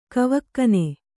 ♪ kavakkane